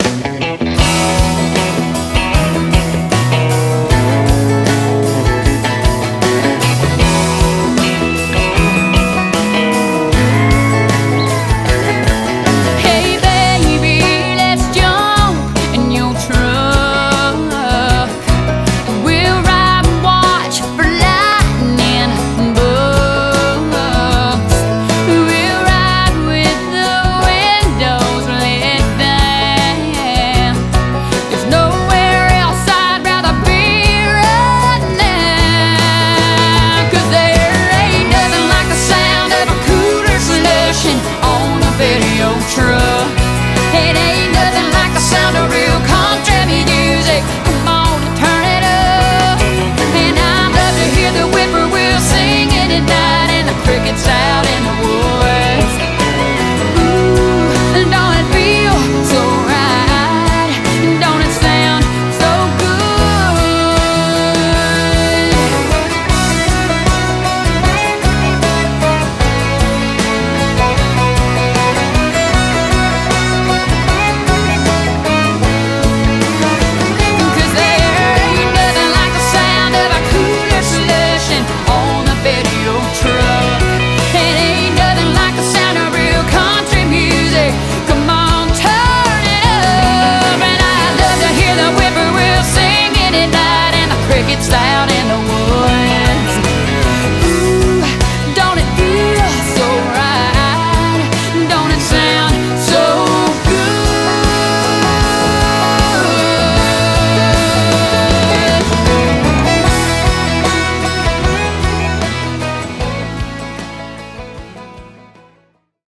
BPM154
Audio QualityMusic Cut